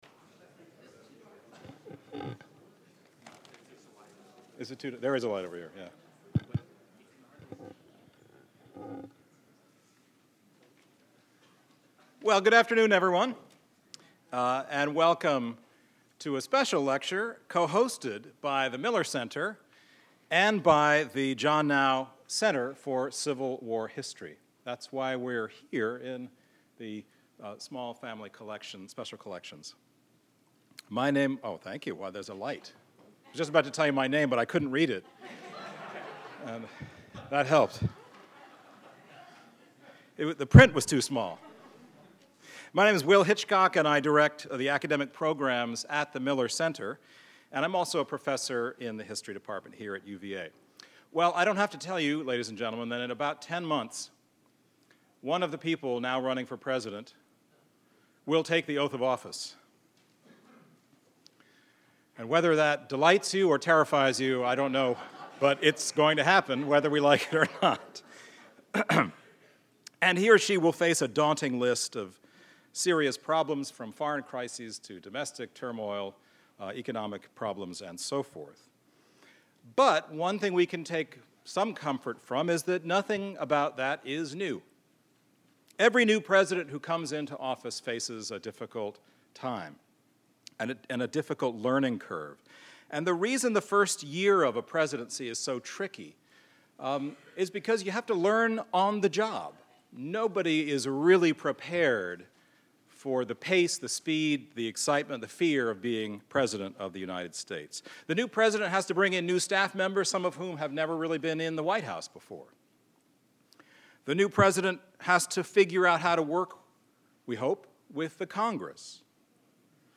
This event will take place in the Auditorium of the Albert and Shirley Small Special Collections Library on Central Grounds and is open to the public.